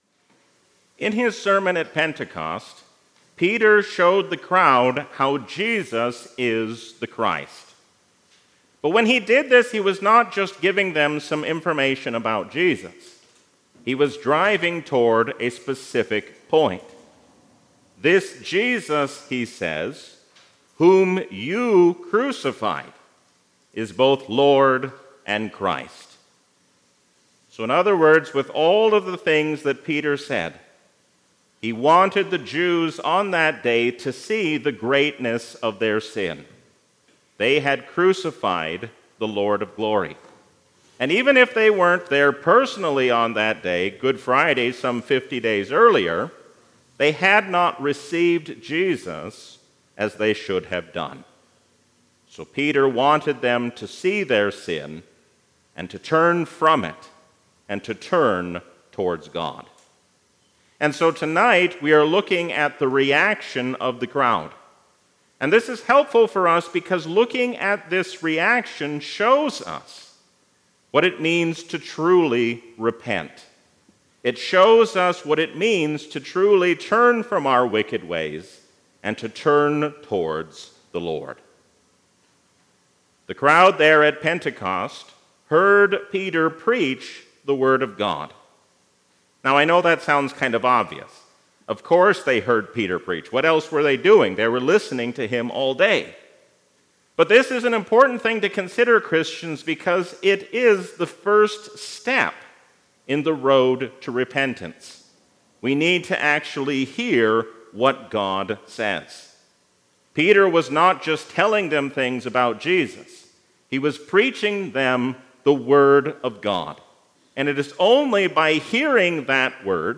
Sermon
A sermon from the season "Trinity 2024."